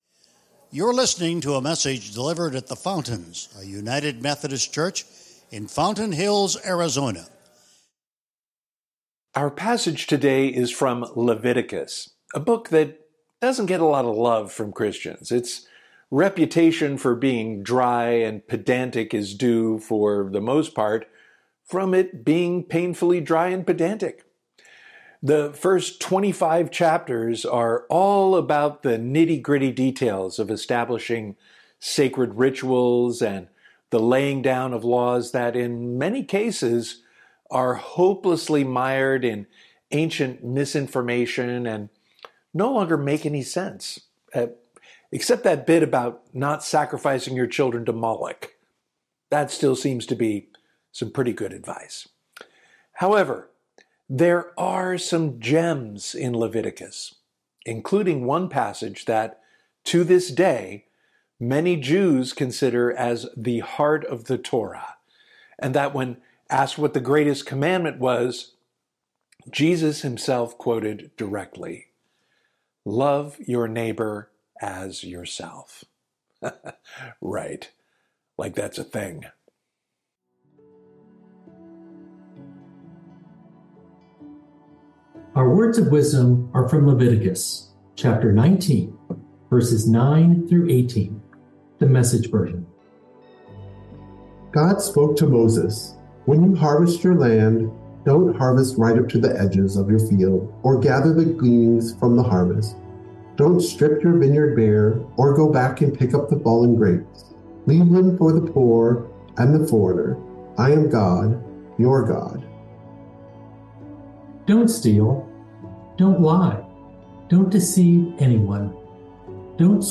Sermons | The Fountains, a United Methodist Church